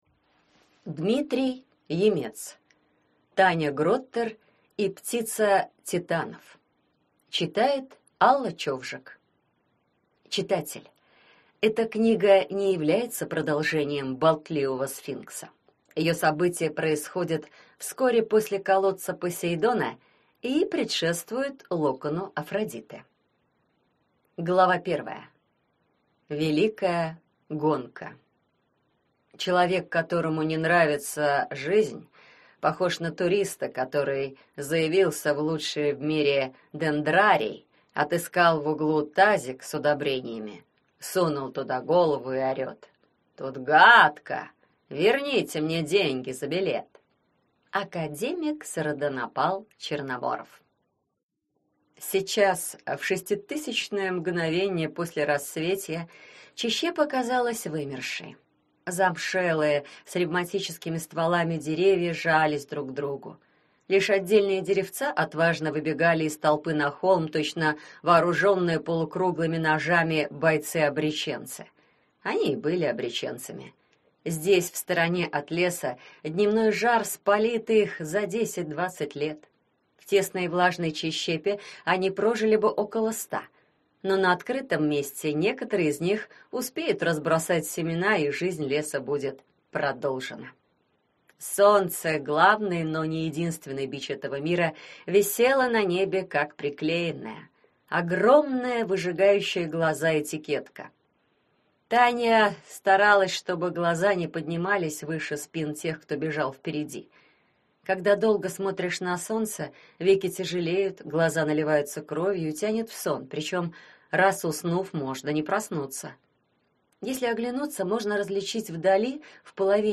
Аудиокнига Таня Гроттер и птица титанов | Библиотека аудиокниг